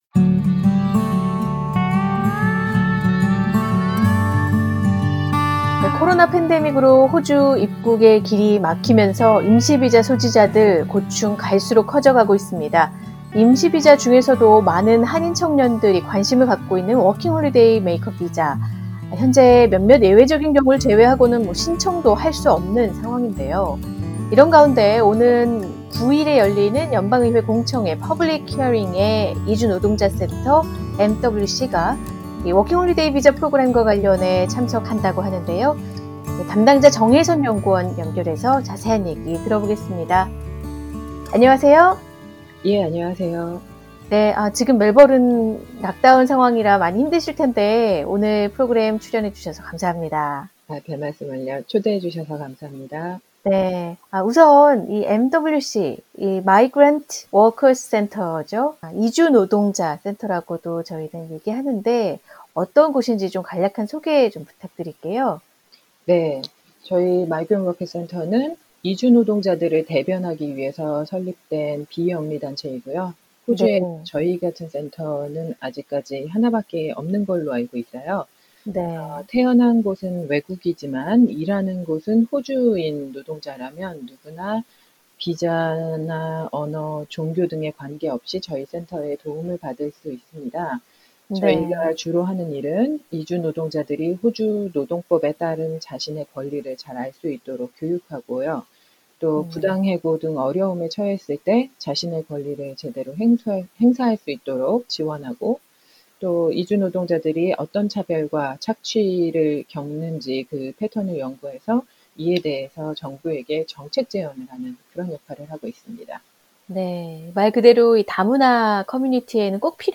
[인터뷰] 워킹홀리데이 비자 제도 개선 여론 확산...연방의회 공청회 본궤도